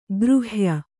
♪ gřhya